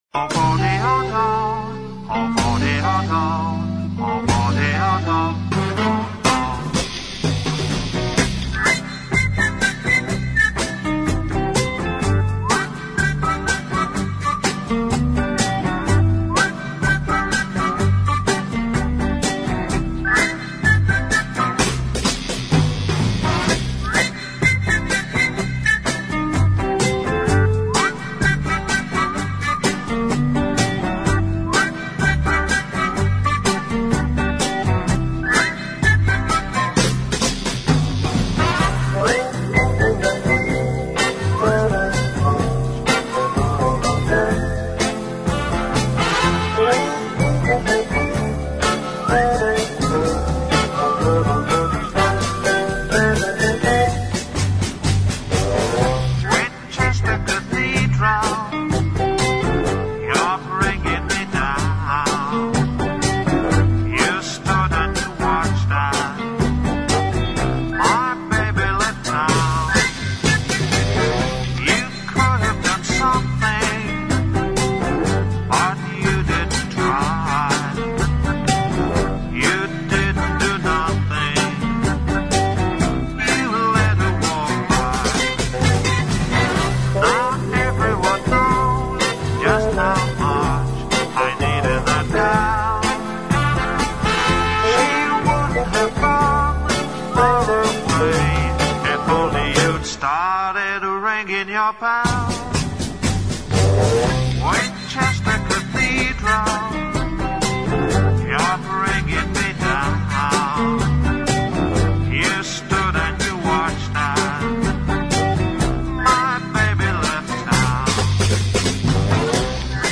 Instrumentais Para Ouvir: Clik na Musica.